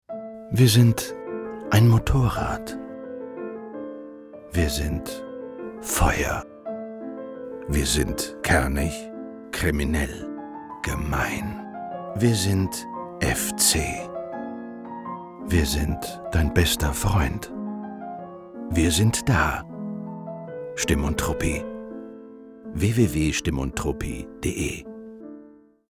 Sprechprobe: Industrie (Muttersprache):
Voice Talent for more than 18 years- German dubbing voice Javier Bardem, friendly, warm voice